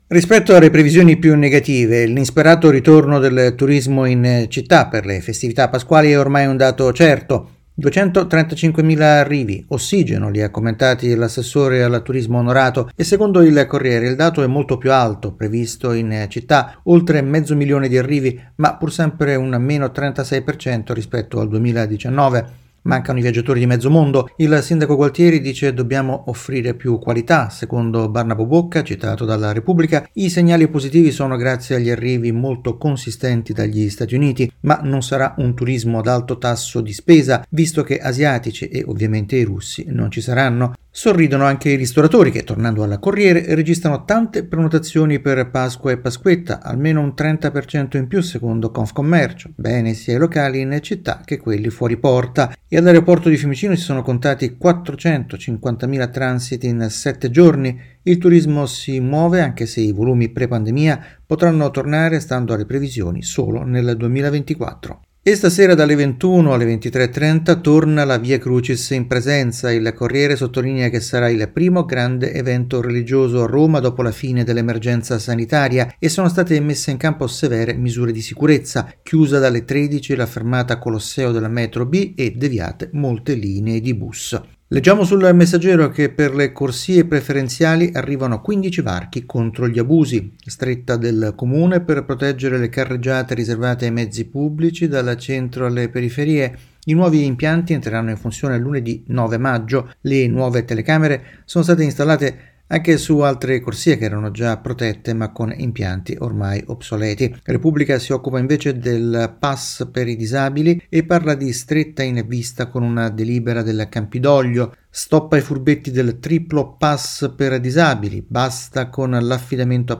rassegna stampa